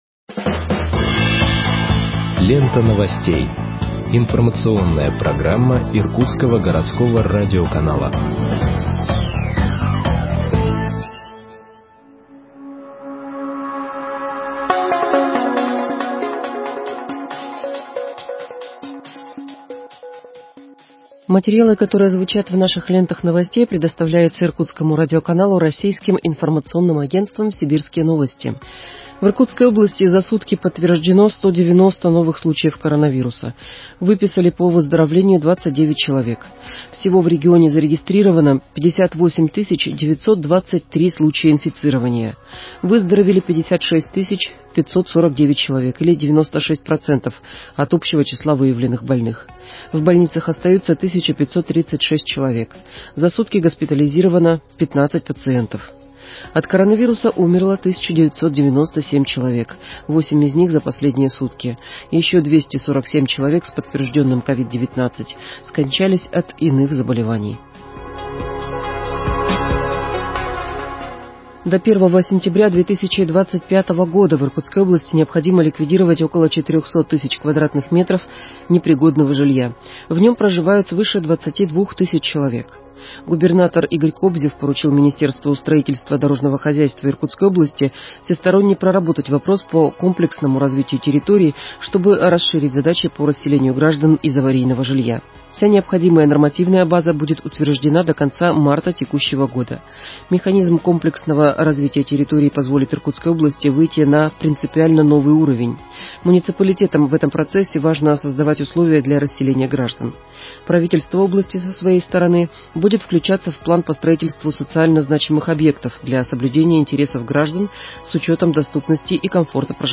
Выпуск новостей в подкастах газеты Иркутск от 15.03.2021 № 2